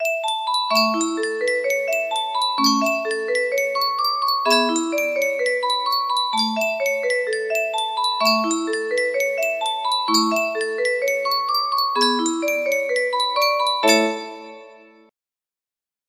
Yunsheng Custom Tune Music Box - How Dry I Am music box melody
Yunsheng Custom Tune Music Box - How Dry I Am
Full range 60